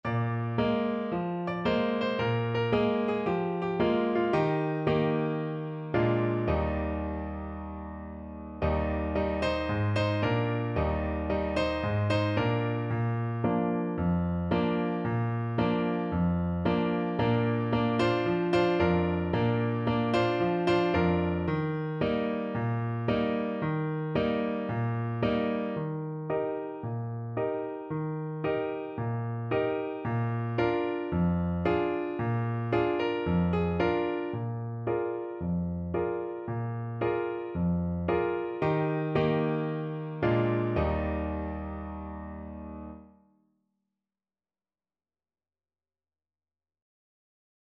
4/4 (View more 4/4 Music)
Cheerfully! =c.112